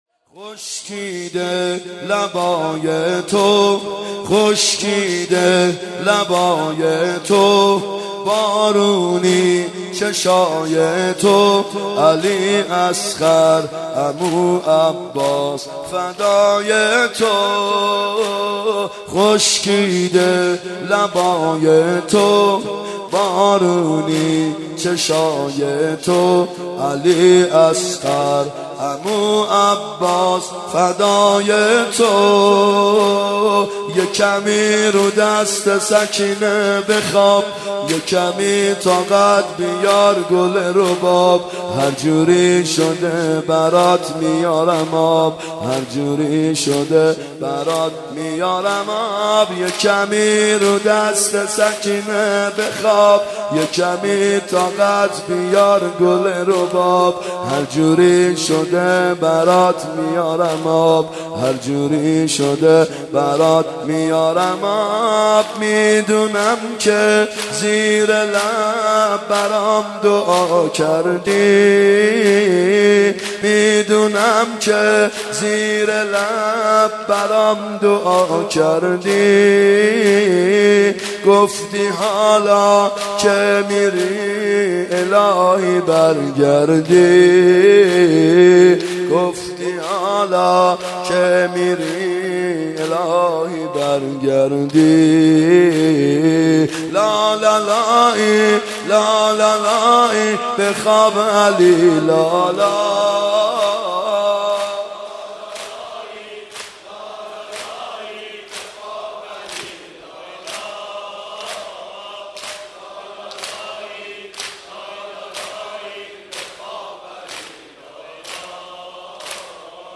محرم 92 شب هفتم زمینه (خشکیده لبای من بارونی چشای تو
محرم 92 ( هیأت یامهدی عج)